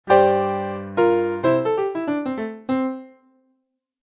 Sus4 or 7sus(4) Chords:
low root octave(1,1) 1,4,5,1 (high) (example in midi and
Note: The second sus-voicing is very "heavy" and powerful; McCoy Tyner uses it with great frequency.